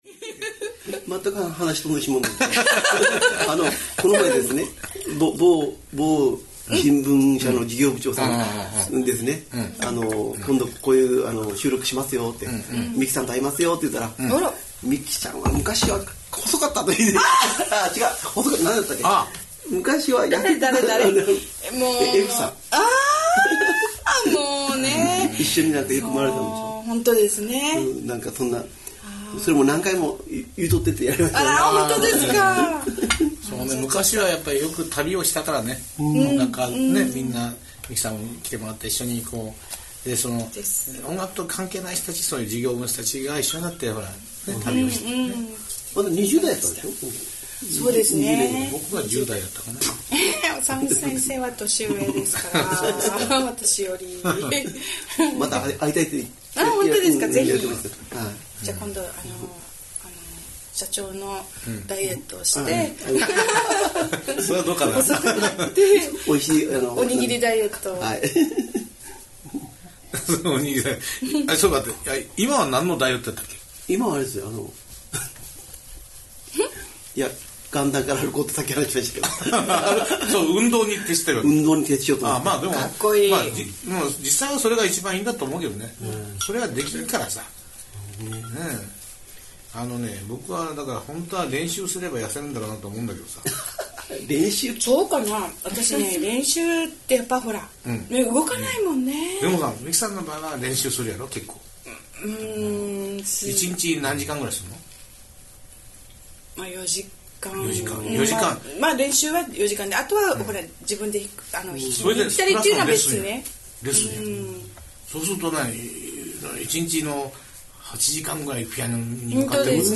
プロのピアニストの演奏をこうやって聞かせていただけるとは、いやもう本当に贅沢です。